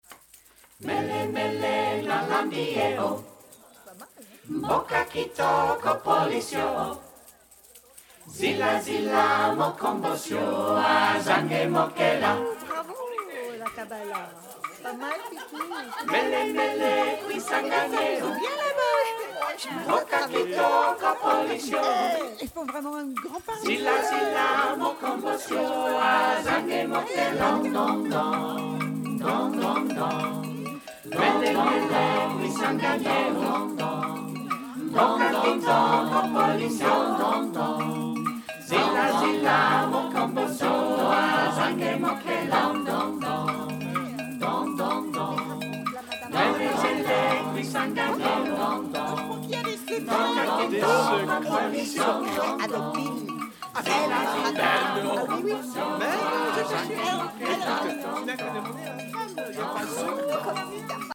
calm and riotous, exultant and tranquil